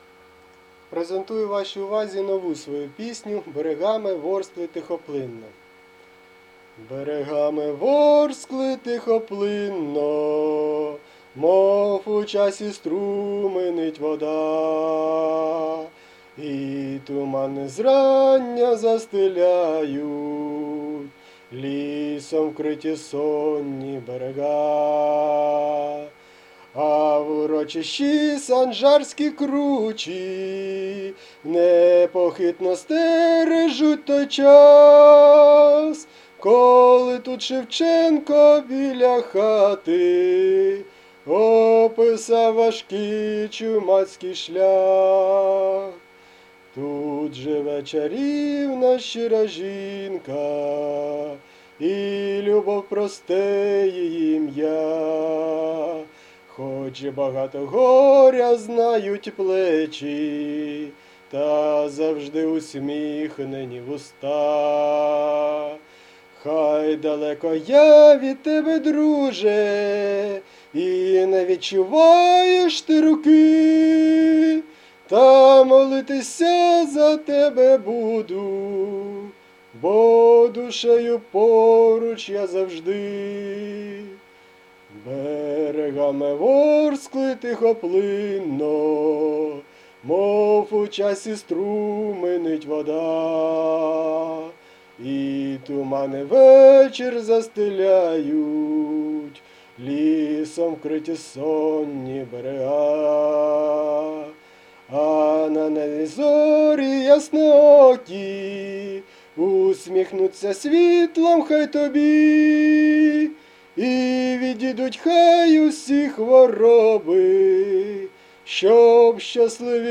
Рубрика: Поезія, Авторська пісня
Хороші слова і співаєте як можете, з душею.
Я прекрасно знаю, що погано співаю.